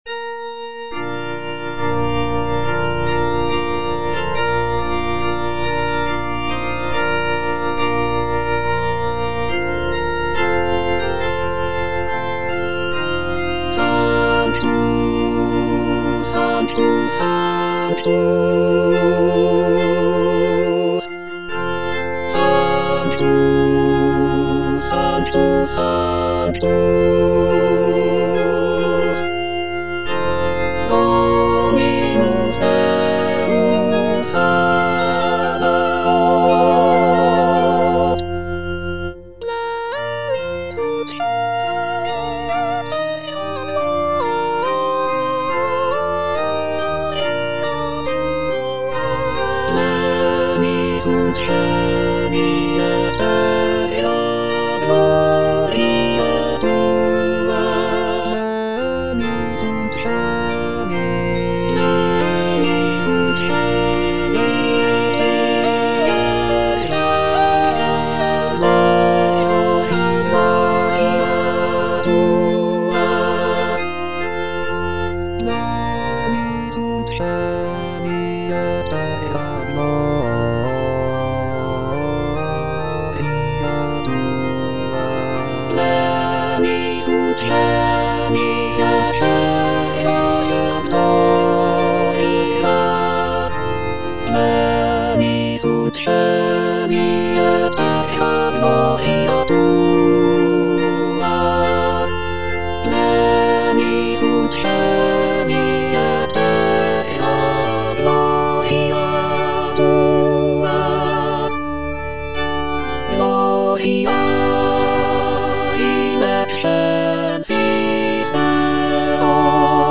All voices
is a sacred choral work rooted in his Christian faith.